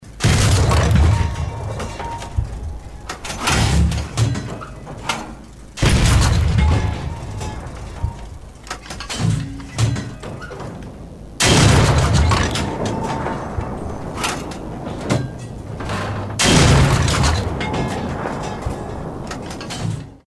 Вы можете слушать онлайн или скачать эффекты в высоком качестве: от глухих залпов тяжелых пушек до резких выстрелов легкой артиллерии.
Выстрел танка 75mm UK